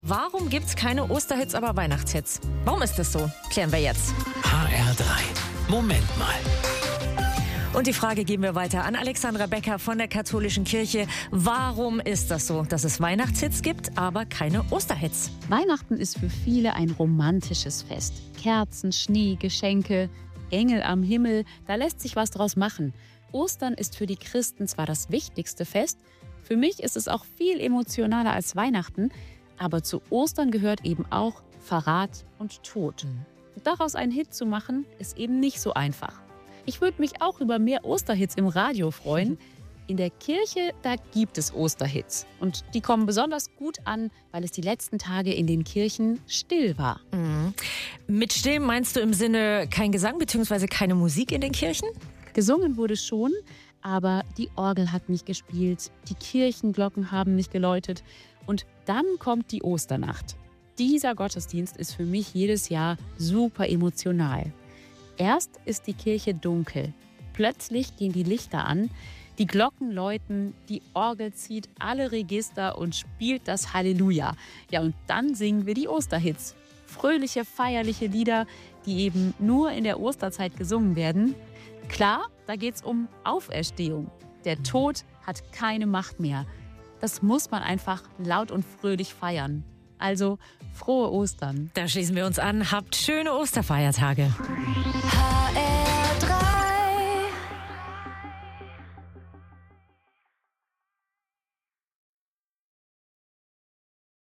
Katholische Pastoralreferentin Frankfurt